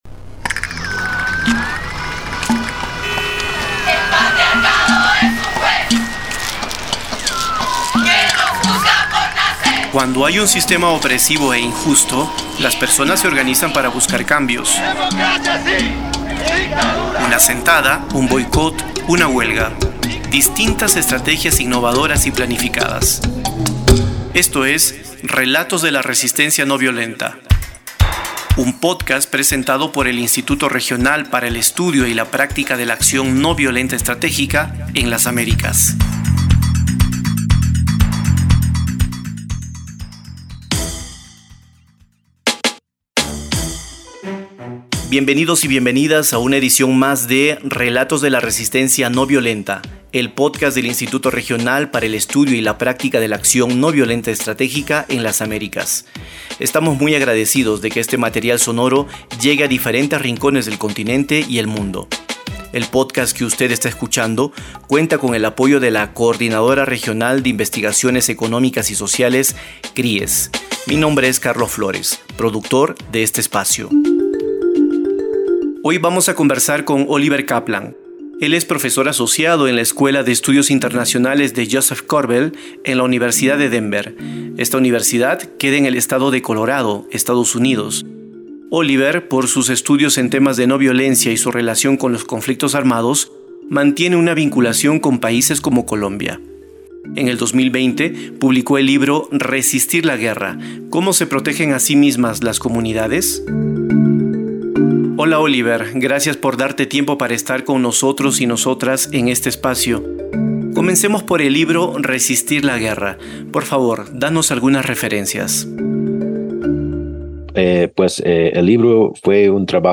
Tamaño: 35.19Mb Formato: Basic Audio Descripción: Entrevista - Acción ...